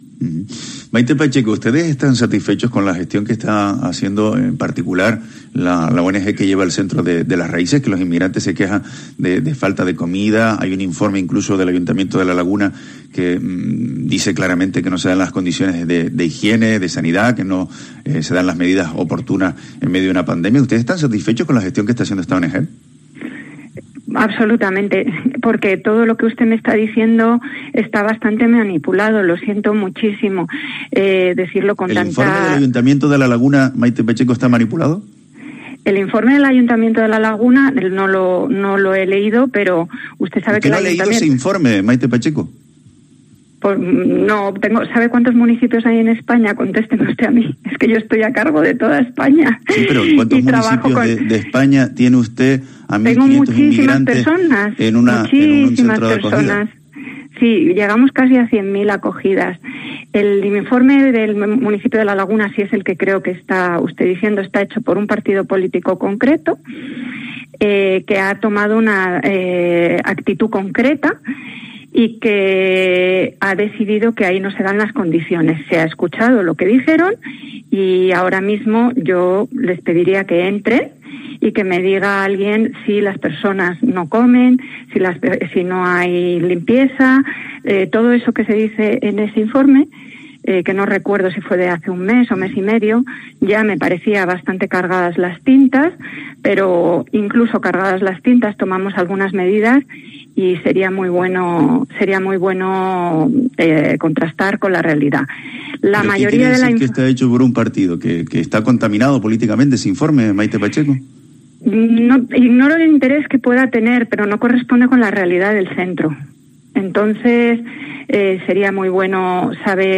Maite Pacheco, directora general de Inclusión y Acción Humanitaria
“Es un informe realizado por un partido político, con una posición concreta sobre el campamento de Las Raíces”, esto es lo que dice Maite Pacheco, directora general de Inclusión y Acción Humanitaria en el Ministerio de Inclusión, Seguridad Social y Migraciones, quien ha pasado por los micrófonos de COPE Canarias, donde ha apuntado que “el informe del consistorio de hace un mes, venía con las tintas cargadas, y no se corresponde con la realidad”.